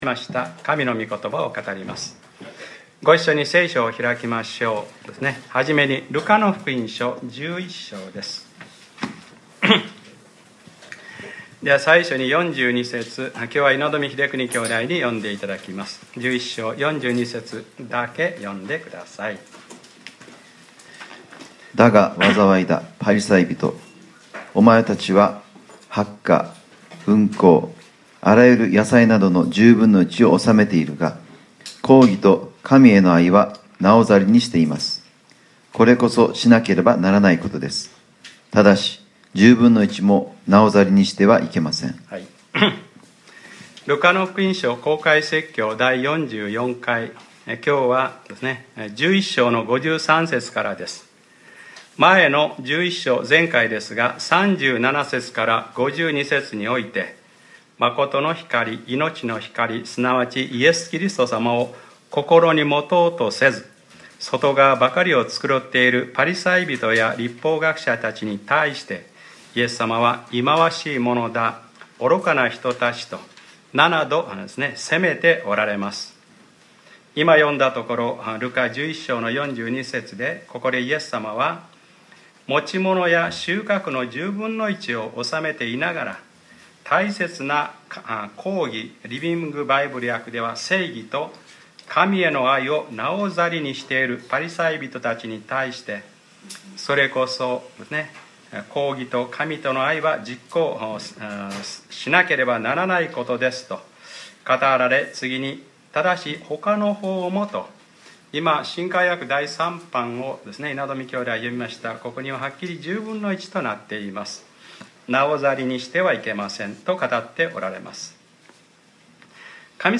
2014年9月 7日（日）礼拝説教 『ルカｰ４４：一羽の雀さえも』